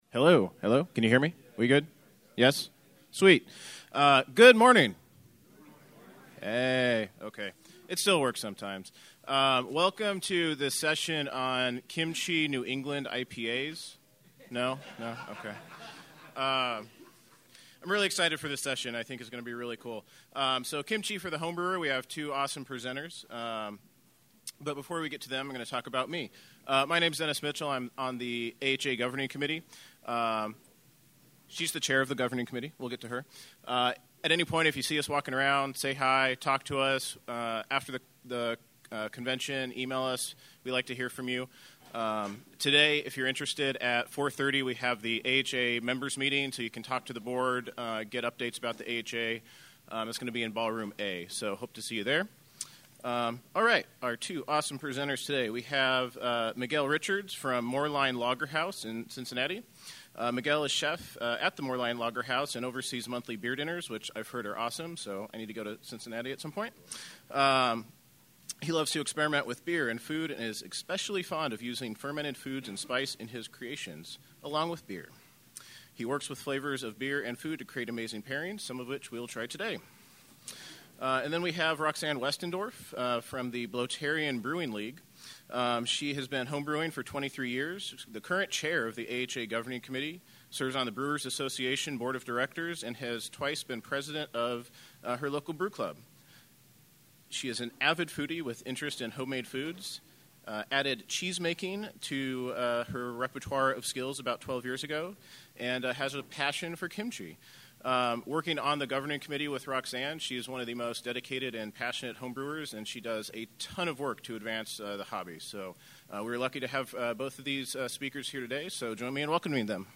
Access recordings from past Homebrew Con seminar presentations.